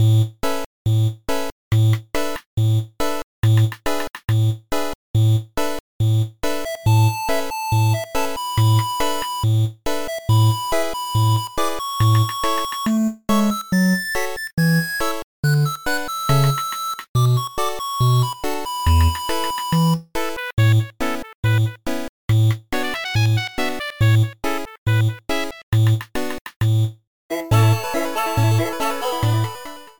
Title Screen music